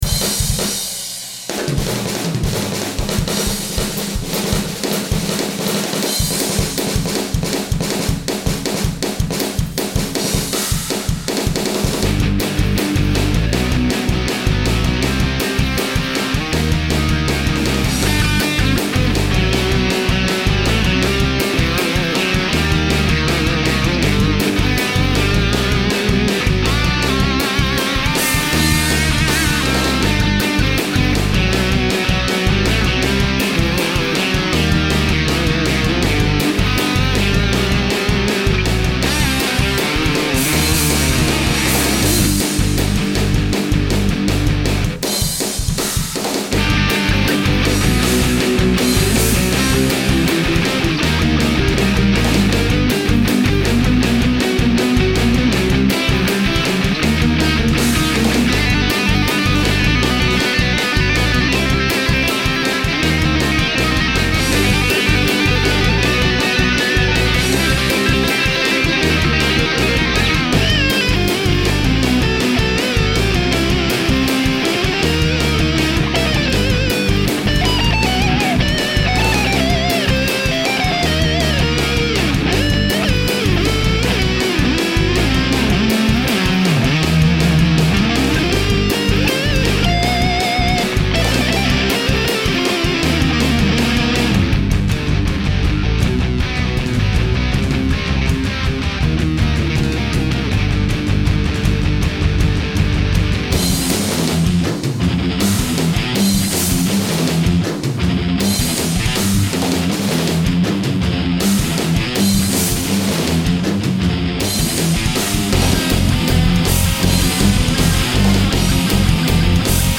So, I pieced this together from stuff I had already recorded and new stuff I could get recorded before the end of the month.Â  It sounds like an audio collage of guitar tones.